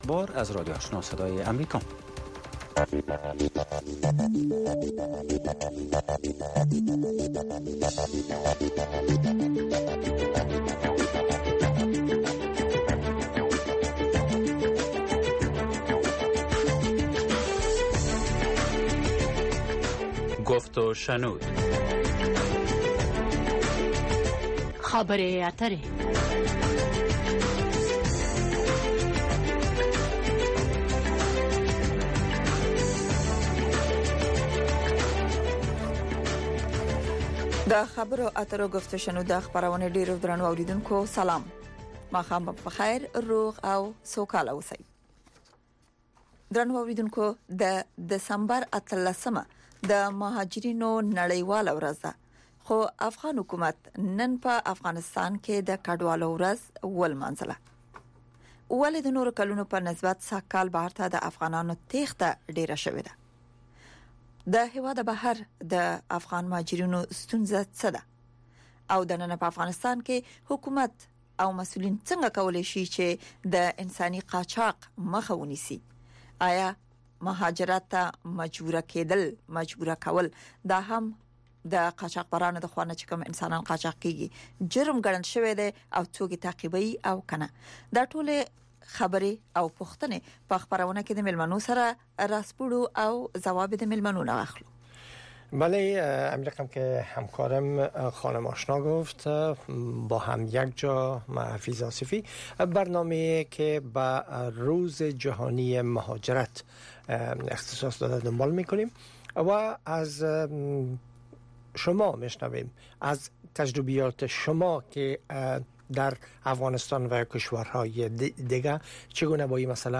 گفت و شنود - خبرې اترې، بحث رادیویی در ساعت ۸ شب به وقت افغانستان به زبان های دری و پشتو است. در این برنامه، موضوعات مهم خبری هفته با حضور تحلیلگران و مقام های حکومت افغانستان بحث می شود.